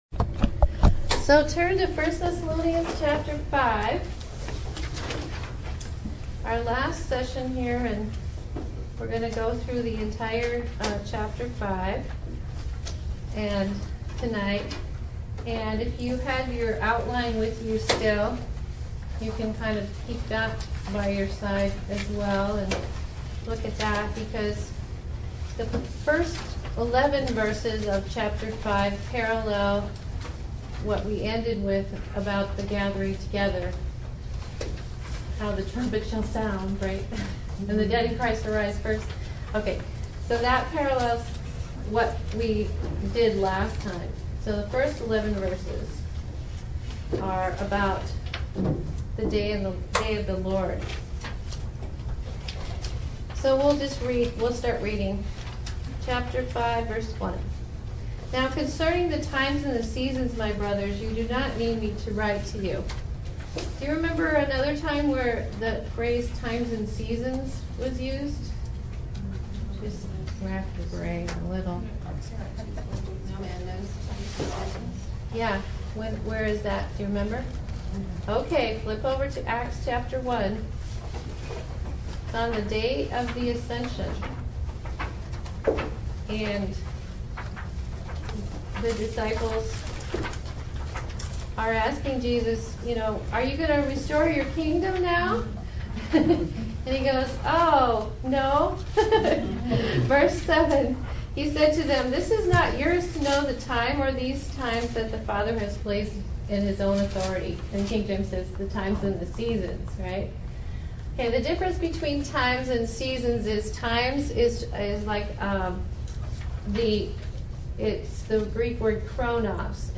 Part 5 of the Thessalonians Audio Teaching Series explores the vital themes of times and seasons, the Day of the Lord, and our lives today.